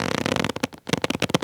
foley_leather_stretch_couch_chair_25.wav